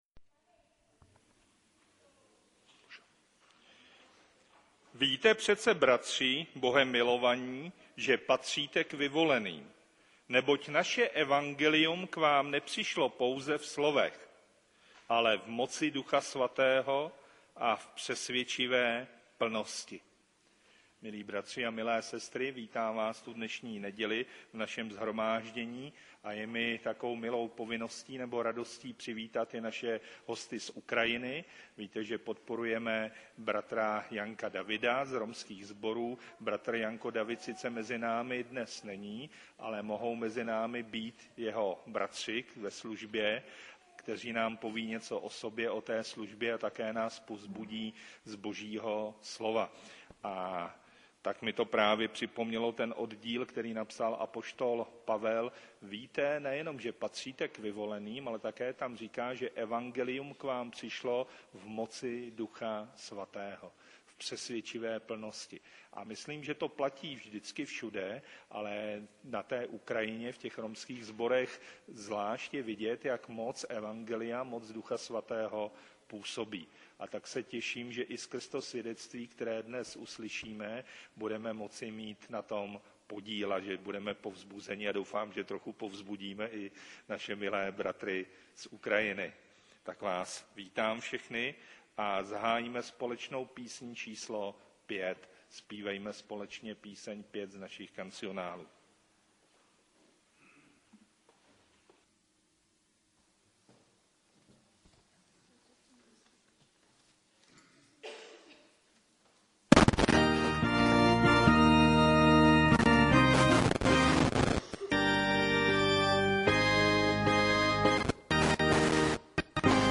romští kazatelé